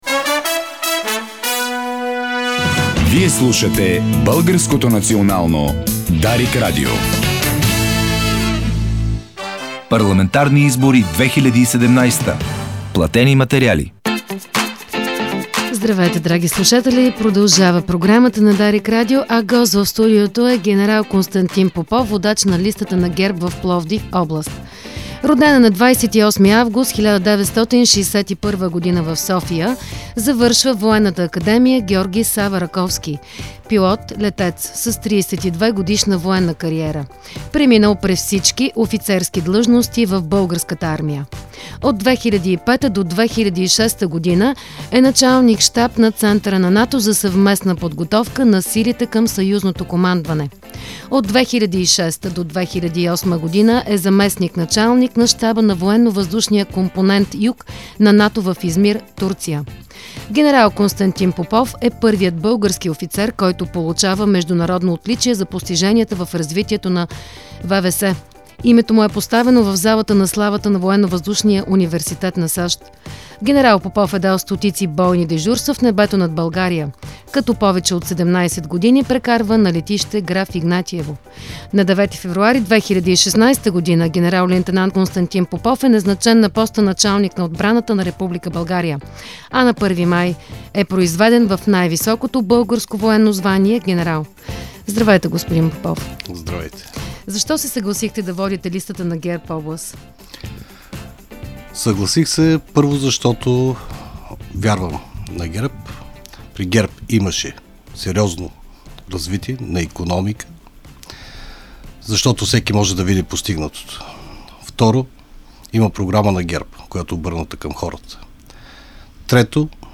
Повишаване на заплатите на военнослужещите с 20% и продължаване на модернизация на армията са едни от основните приоритети, заложени в предизборната програма на ПП ГЕРБ. Това заяви в студиото на Дарик радио ген. Константин Попов - водач на листата на ГЕРБ в Пловдив област.